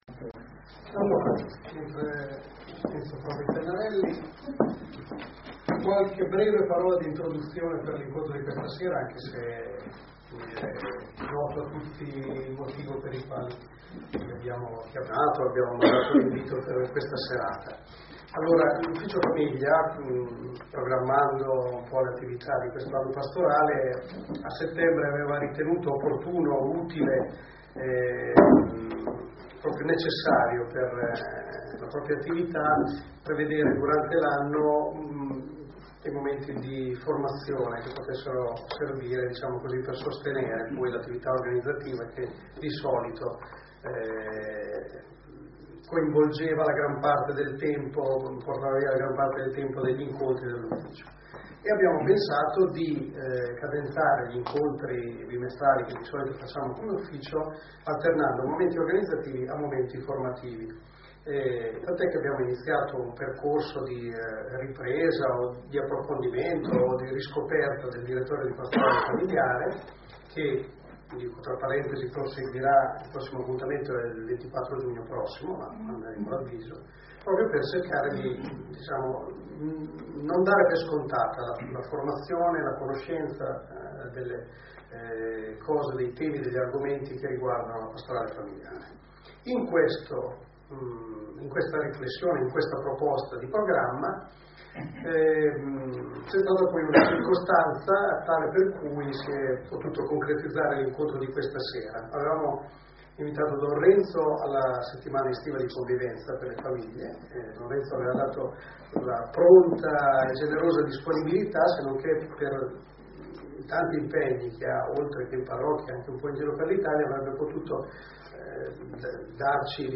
Sala Parrocchiale Novafeltria L'ufficio per la Pastorale della Famiglia della Diocesi di San Marino Montefeltro ha proposto un incontro allargato a tutte le famiglie che si occupano di pastorale familiare